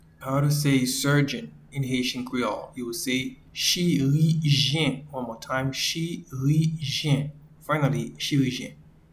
Pronunciation and Transcript:
Surgeon-in-Haitian-Creole-Chirijyen.mp3